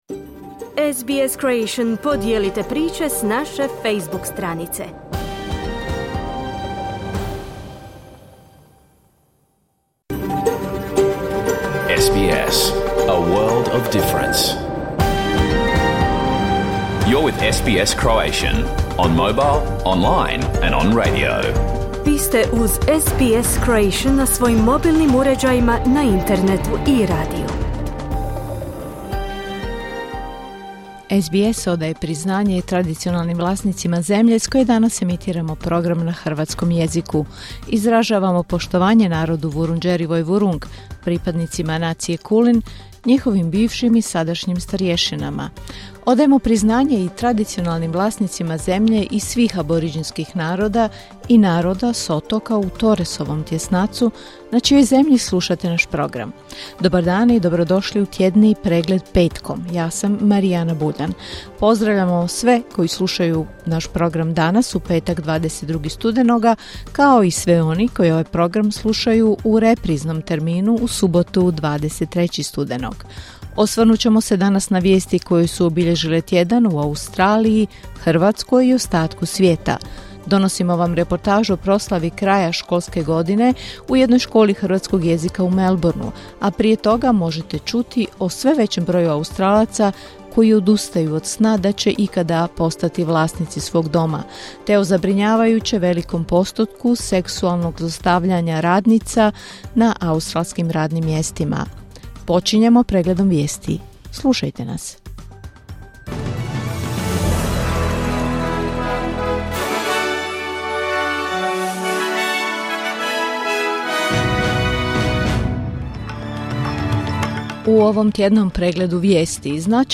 Vijesti i aktualne teme iz Australije, Hrvatske i ostatka svijeta. Emitirano uživo na radiju SBS1 u 11 sati po istočnoaustralskom vremenu.